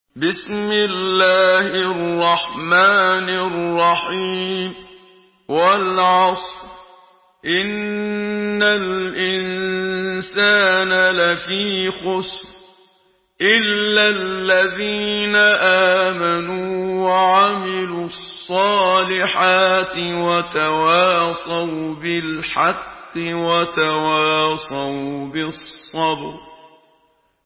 Surah আল-‘আসর MP3 in the Voice of Muhammad Siddiq Minshawi in Hafs Narration
Murattal